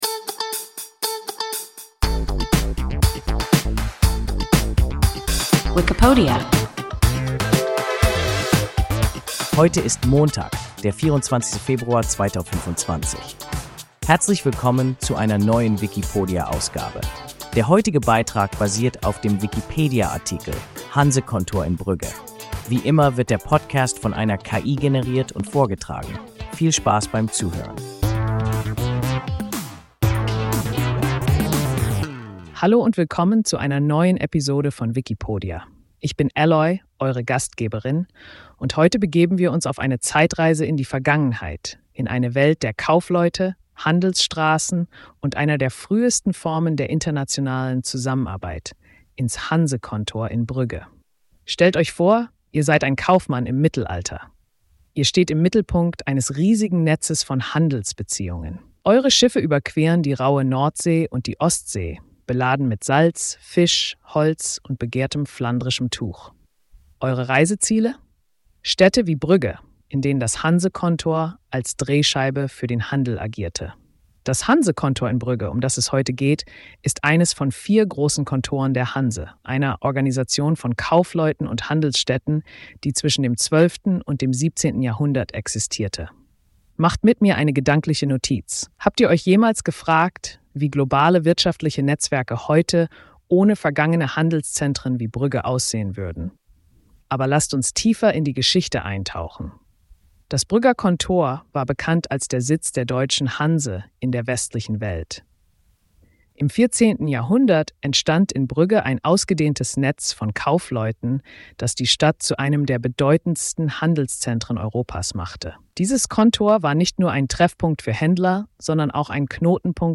Hansekontor in Brügge – WIKIPODIA – ein KI Podcast